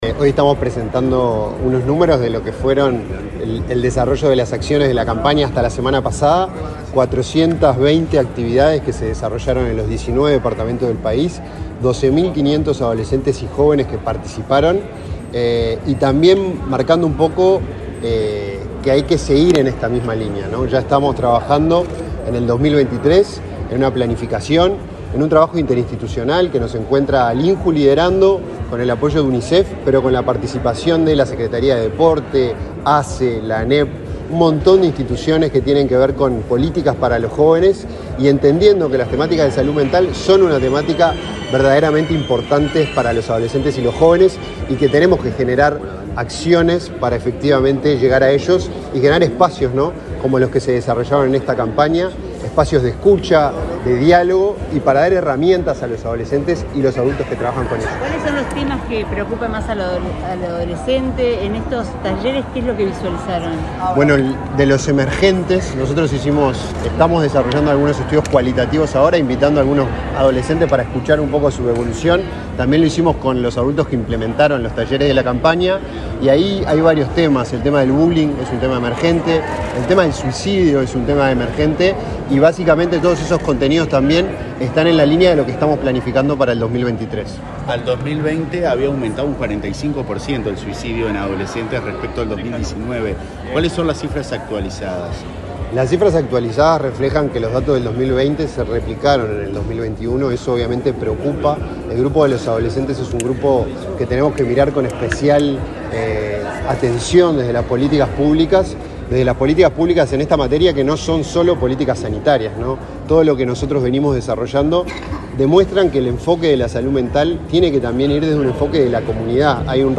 Declaraciones del director del INJU, Felipe Paullier
El director del Instituto Nacional de la Juventud (INJU), Felipe Paullier, dialogó con la prensa luego de participar del acto de balance de la campaña